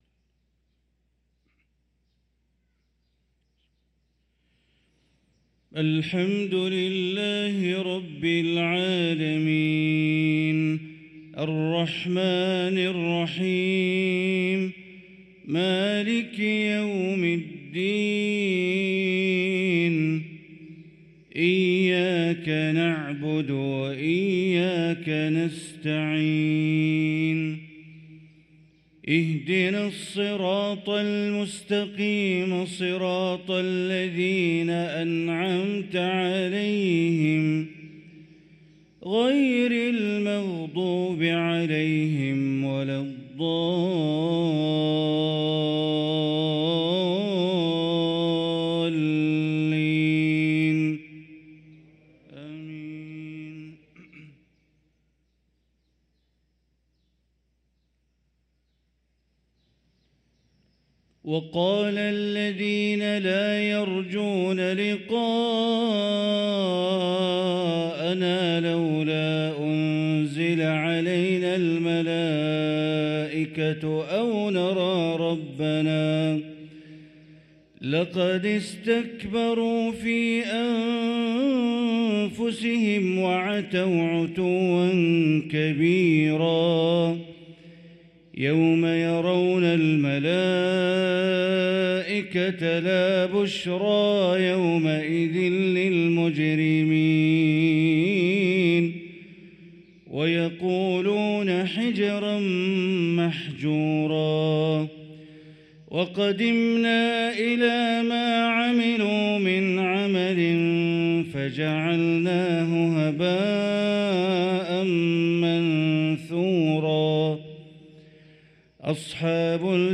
صلاة الفجر للقارئ بندر بليلة 18 جمادي الأول 1445 هـ